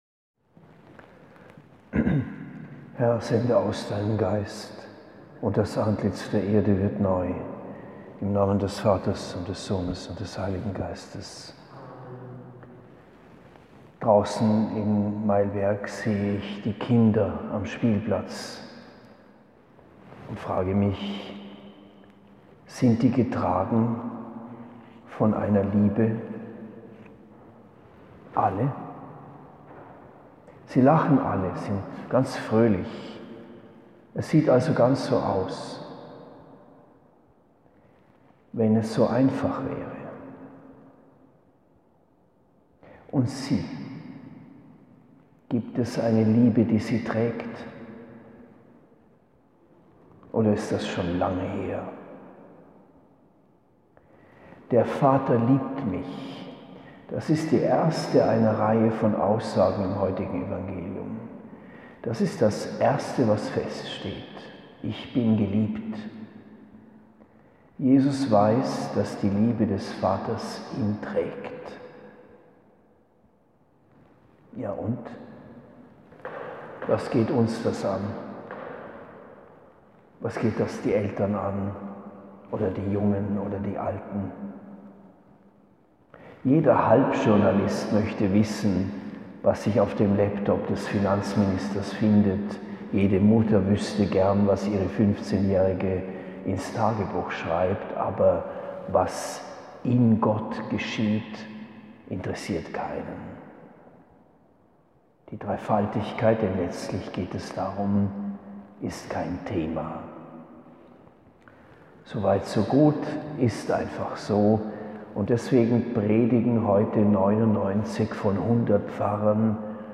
Zum mündlichen Vortrag bestimmt, verzichtet dieser Text auf Quellenangaben.